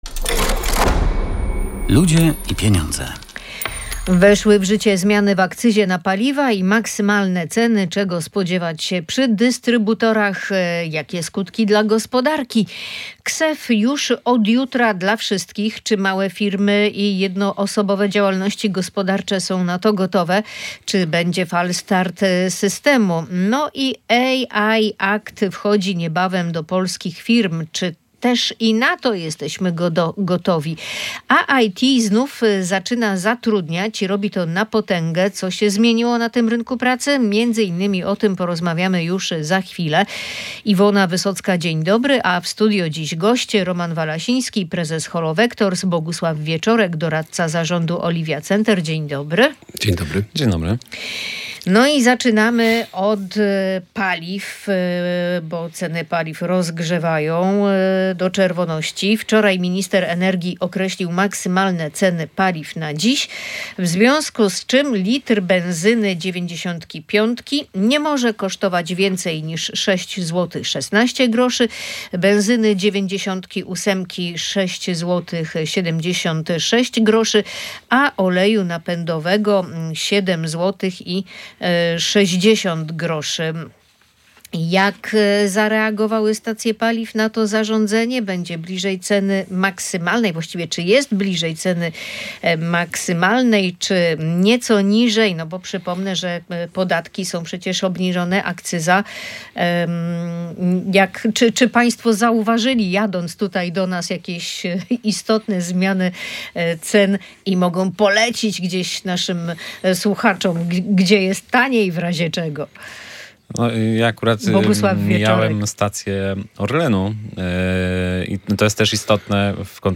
odpowiedzieli goście audycji „Ludzie i Pieniądze”